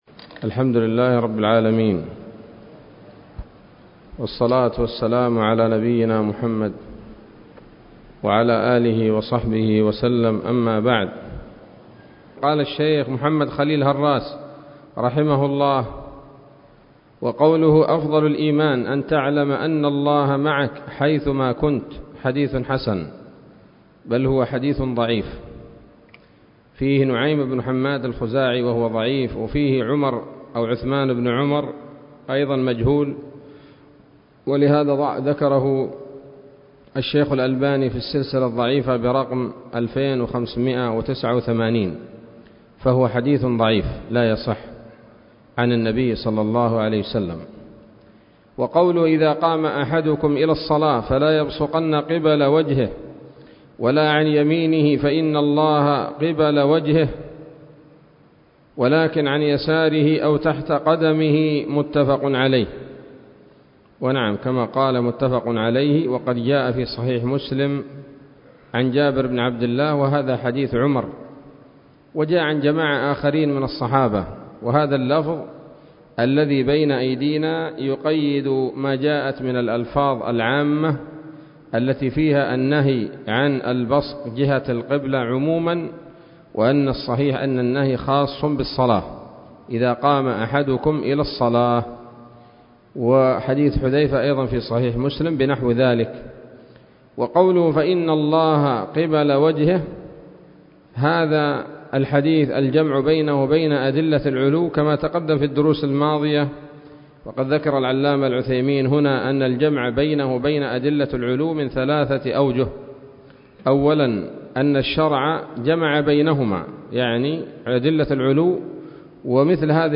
الدرس الثامن والثمانون من شرح العقيدة الواسطية للهراس